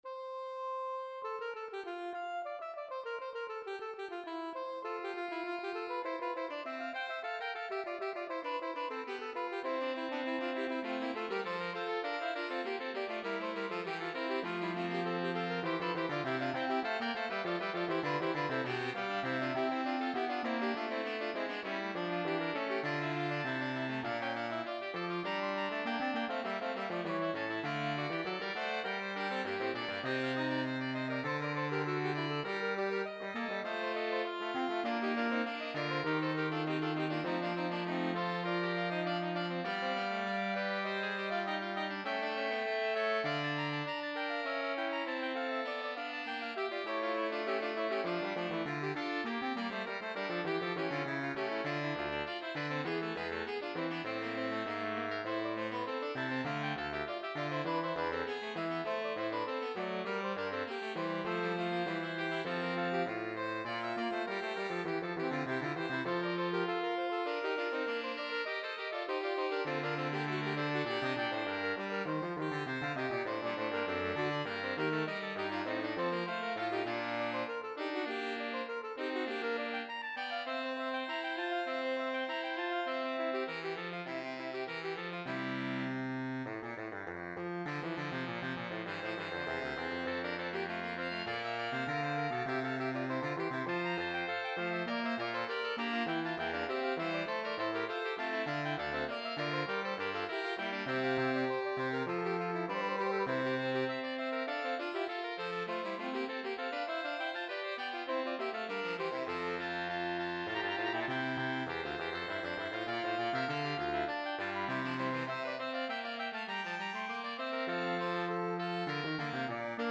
for Saxophone Quartet
Voicing: Saxophone Quartet (SATB)